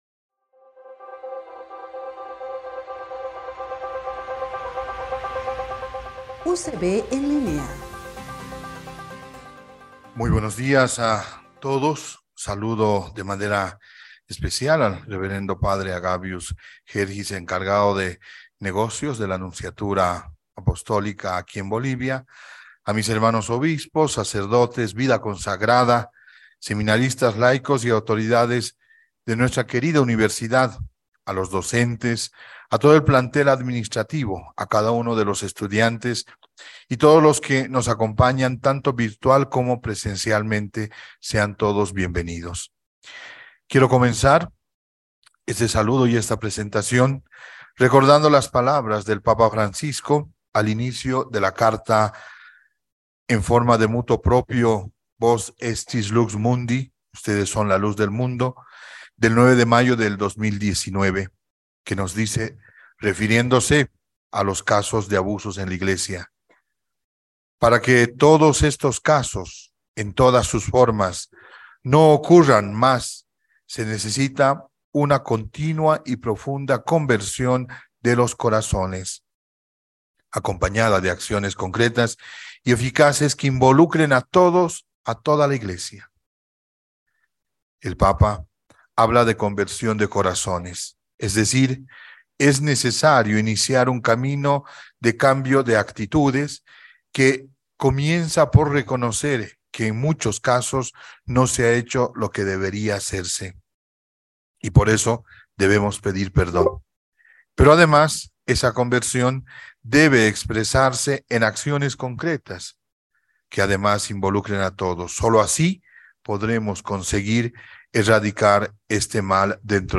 Audio Palabras Mons. Giovani Arana